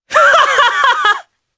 Morrah-Vox_Happy3_jpb.wav